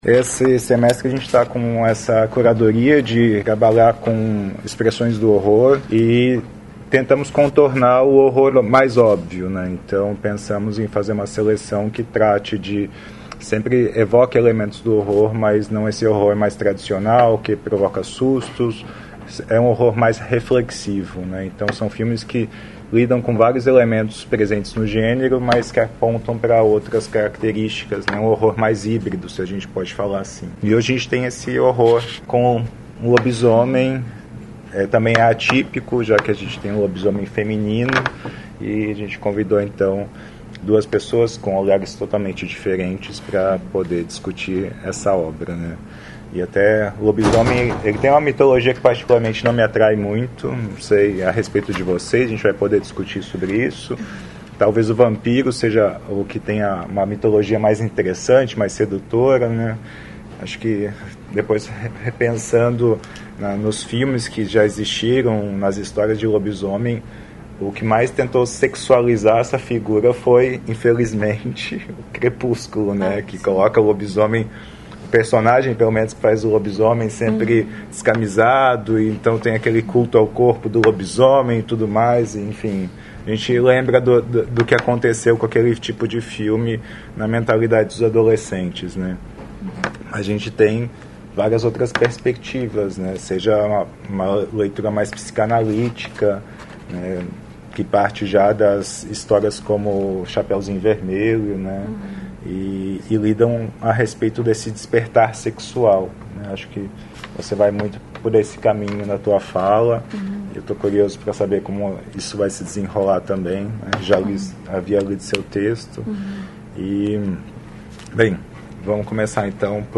Comentários dos debatedores convidados
na sessão de exibição e debate do filme "Quando os animais sonham"